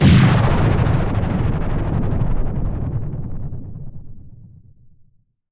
Power Laser Guns Demo
Plasm_gun27.wav